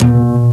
DBLBASS1.WAV